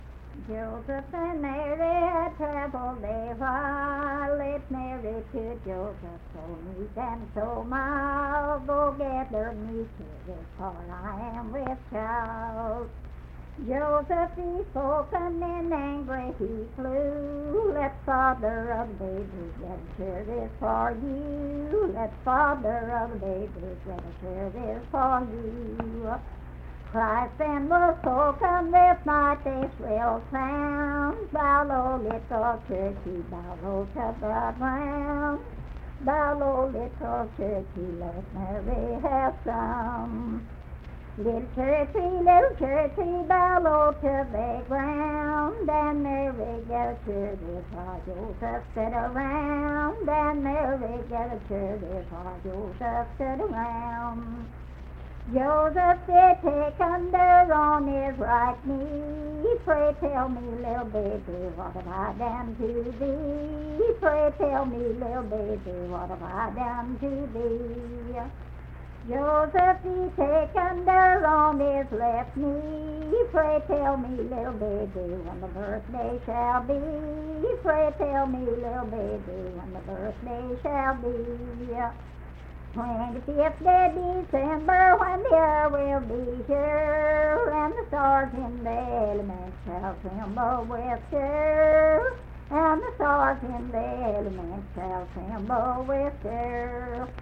Unaccompanied vocal music
Verse-refrain, 7(3w/R).
Hymns and Spiritual Music
Voice (sung)
Logan County (W. Va.)